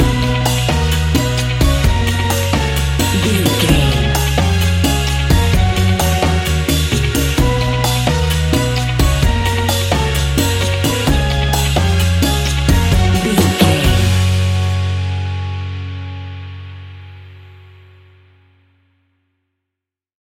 A warm and upbeat piece of calypso summer sunshine music!
That perfect carribean calypso sound!
Uplifting
Ionian/Major
F#
steelpan
percussion
brass
guitar